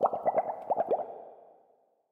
bubble_column
upwards_ambient4.ogg